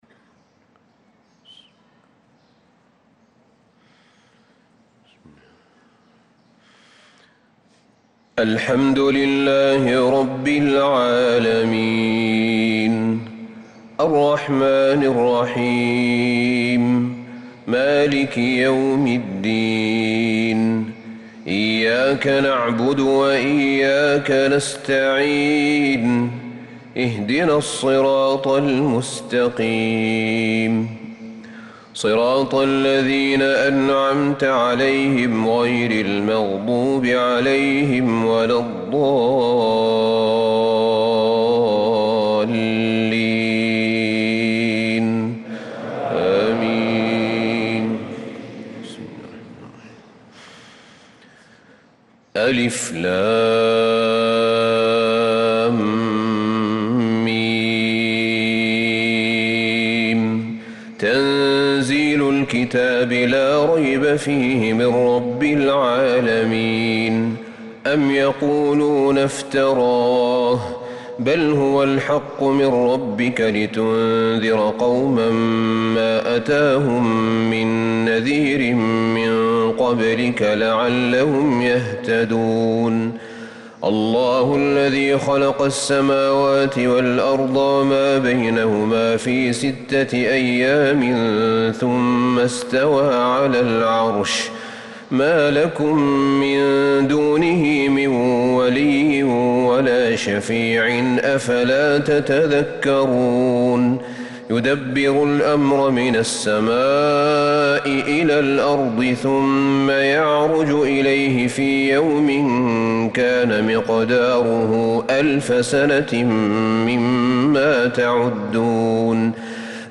صلاة الفجر للقارئ أحمد بن طالب حميد 20 محرم 1446 هـ
تِلَاوَات الْحَرَمَيْن .